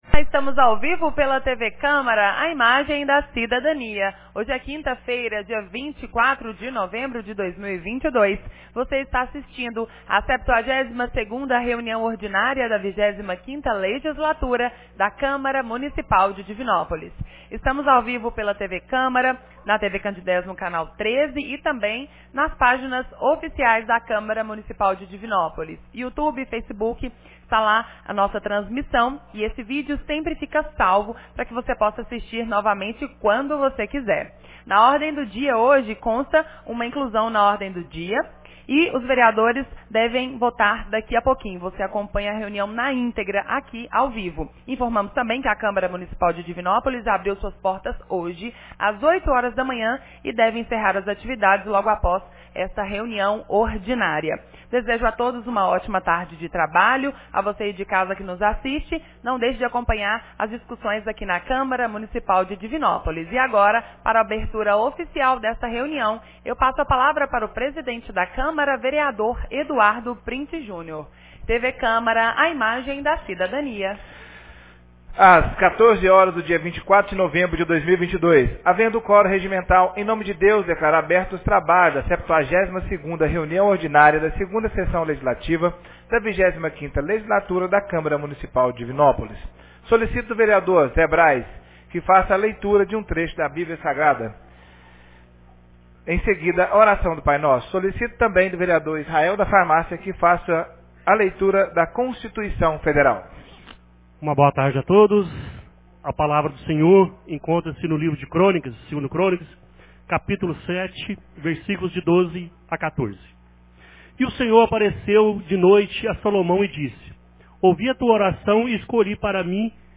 72ª Reunião Ordinária 24 de novembro de 2022